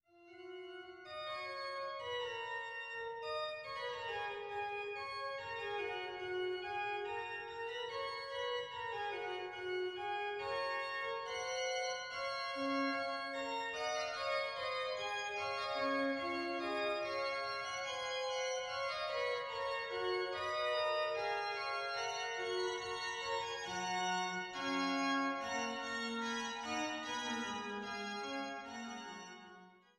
Rötha/St. Marien